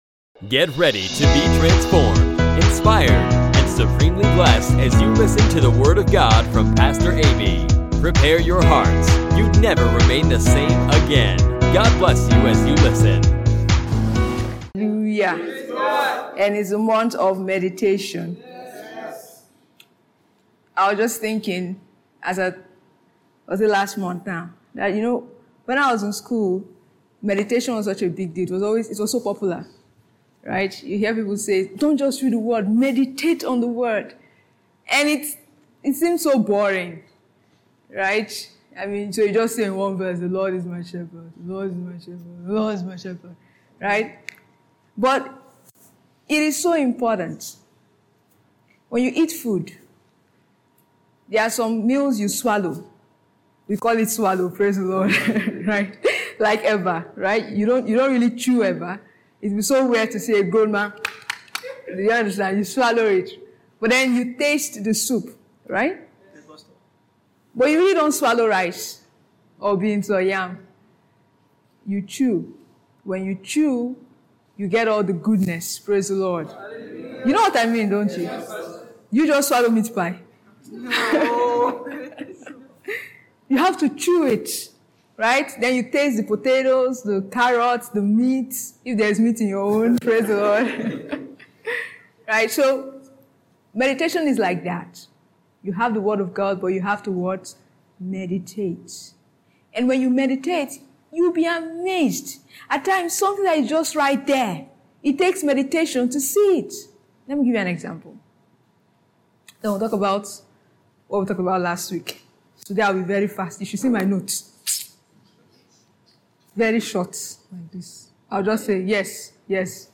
The word of God shows us how to prosper- emphatically, he instructs us to meditate on the word day and night; our meditations not only usher us into prosperity but give us the intelligence to navigate in and maintain our prosperity. Find out more in this insightful teaching by Pastor.